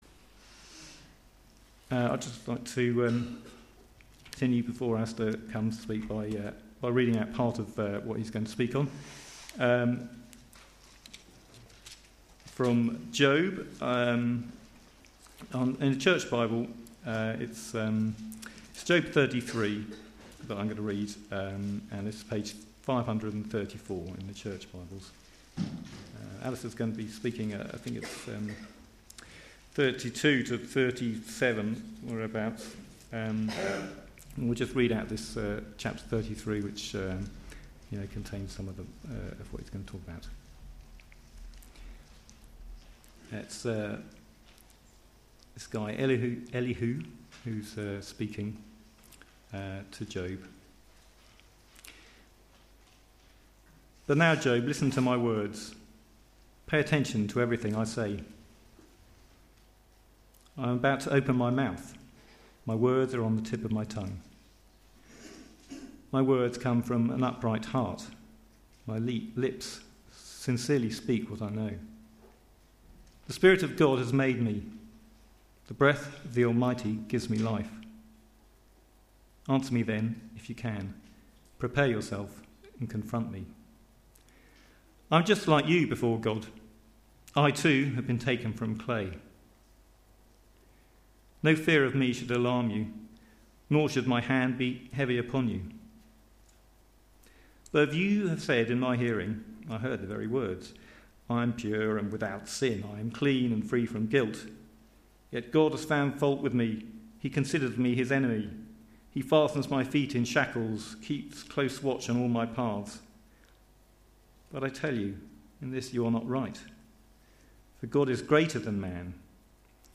Media for Sunday Service